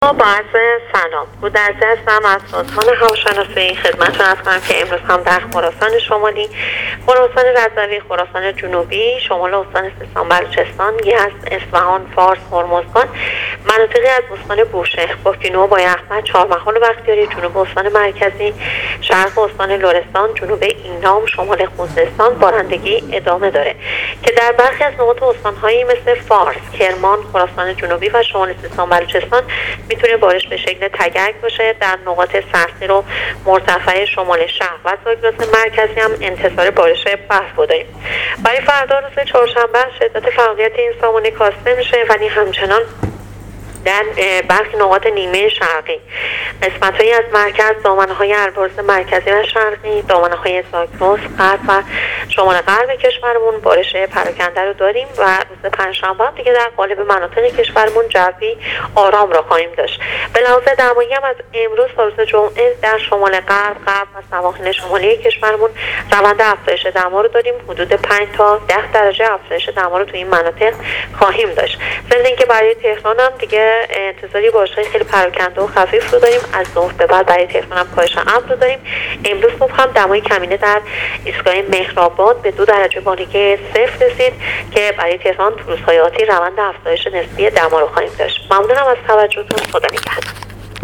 در گفتگو با راديو اينترنتی پايگاه خبری